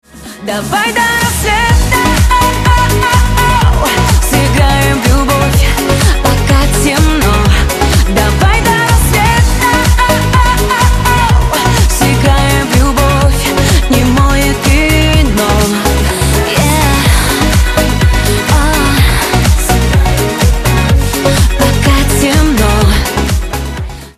• Качество: 128, Stereo
поп
ритмичные
женский вокал
зажигательные